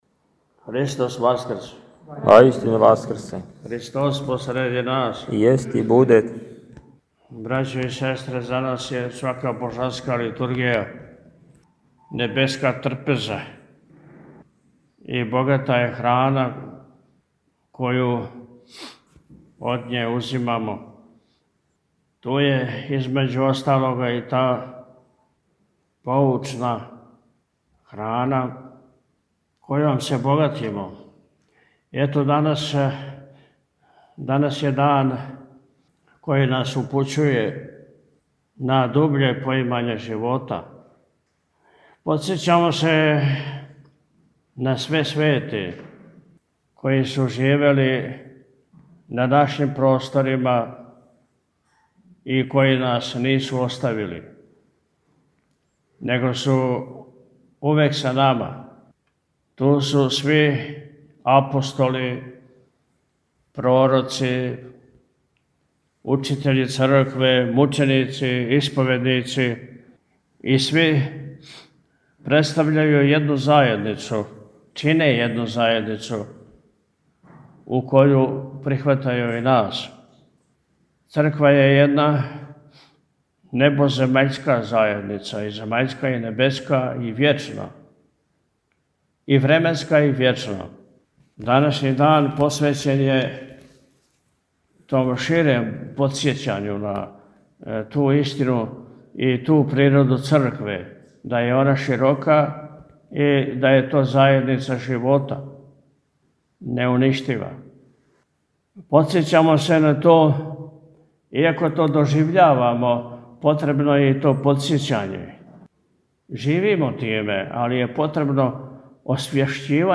Након заамвоне молитве служен је помен за све преминуле чија се имена помињу у овој светој обитељи.
У пастирској беседи Митрополит Атанасије је, између осталог, рекао: – Данас је дан који нас упућује на дубље поимање живота.